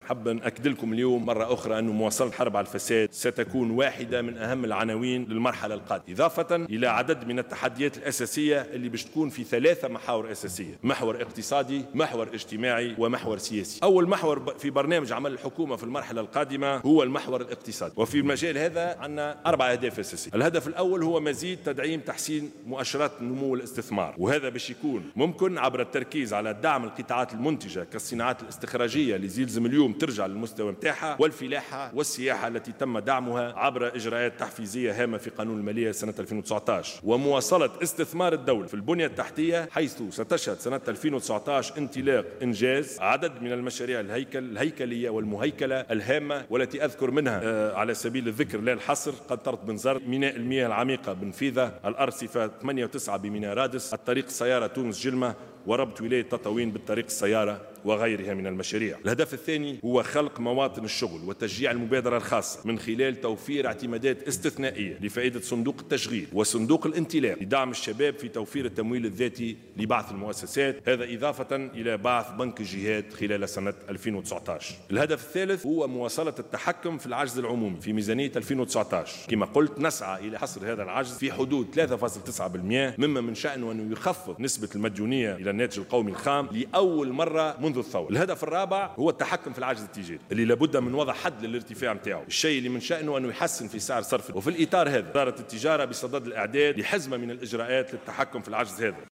وأوضح خلال كلمته في مفتتح الجلسة العامة اليوم الاثنين المخصصة للمصادقة على التحوير الوزاري، أن حكومته تراهن على تحقيق 4 أهداف أساسية على المستوى الاقتصادي، أولها مزيد تحسين مؤشرات النمو والاستثمار، ثم خلق مواطن شغل وتشجيع المبادرة الخاصة، وثالثا مواصلة التحكم في العجز العمومي في ميزانية 2019 وحصره في 3.9 بالمائة، ثم أخيرا التحكم في العجز التجاري.